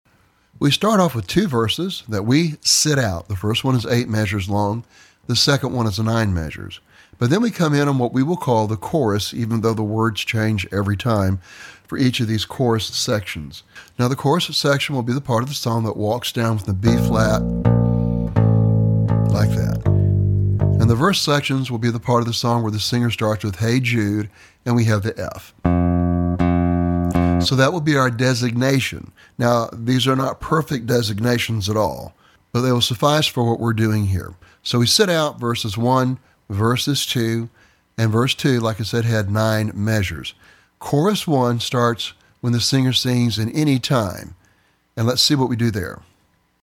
Lesson Sample
For Bass Guitar.